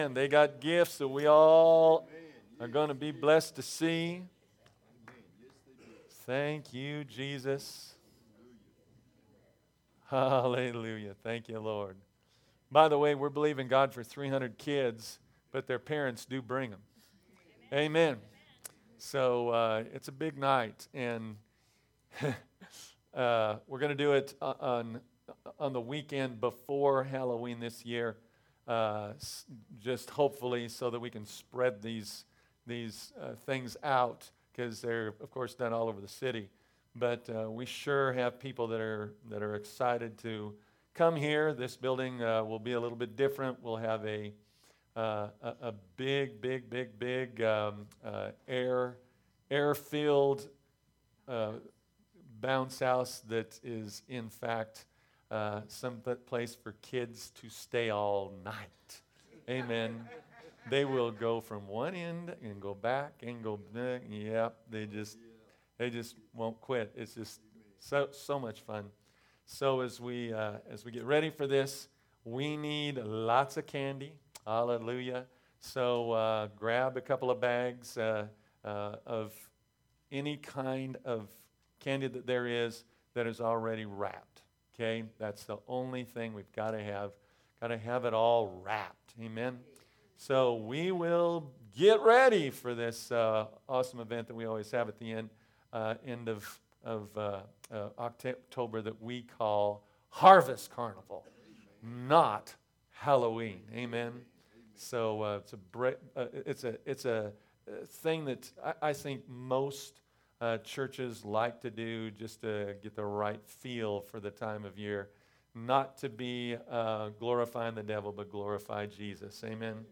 Sermons | Victory Christian Fellowship